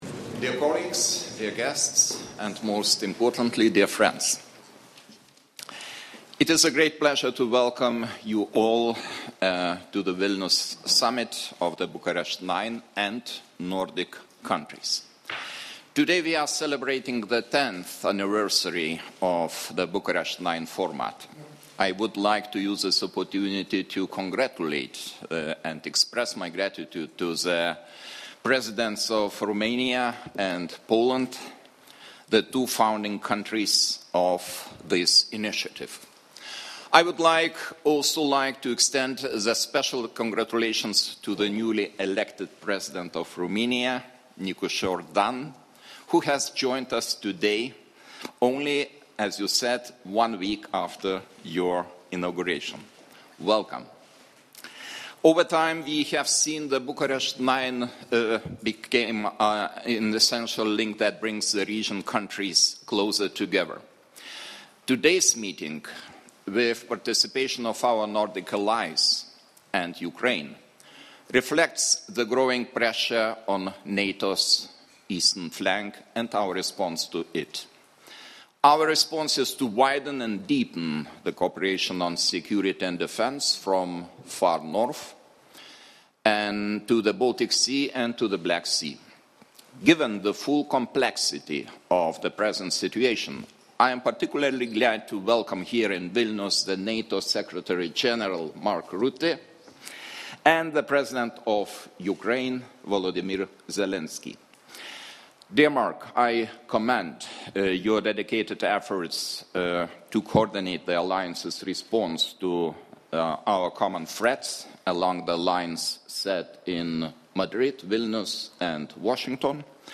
Joint press conference by NATO Secretary General Mark Rutte with the President of Lithuania, the President of Poland, the President of Romania, the Prime Minister of Denmark, and the President of Ukraine at the B9-Nordic Summit in Vilnius 02 Jun. 2025 | download mp3